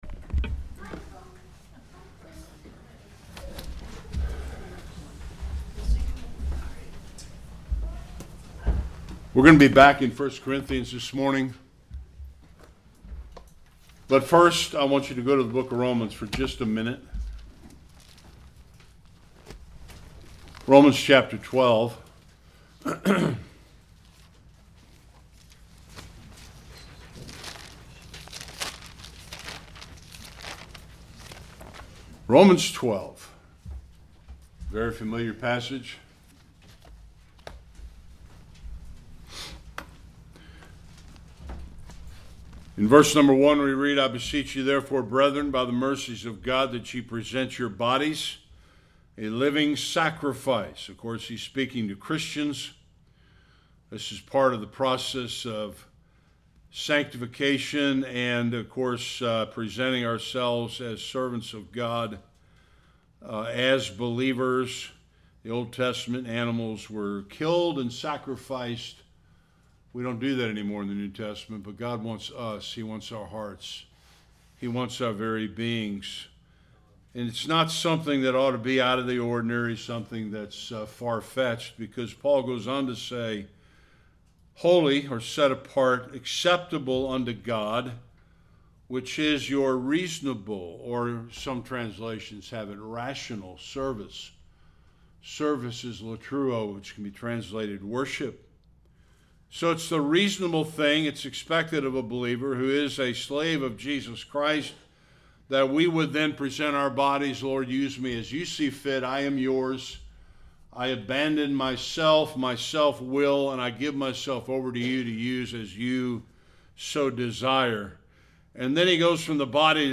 6-31 Service Type: Sunday Worship The church at Corinith had a problem with pride and arrogance.